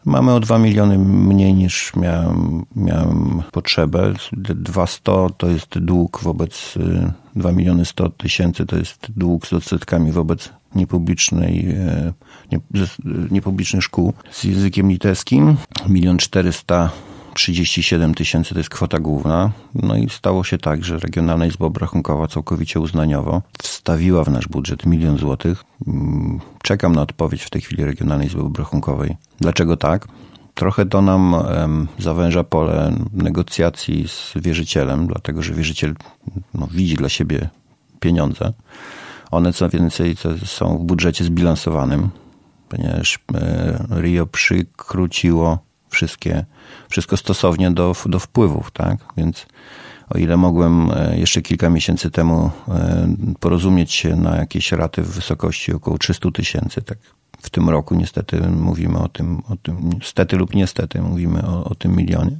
Szczegóły burmistrz przedstawił we wtorek (21.03) w Radiu 5.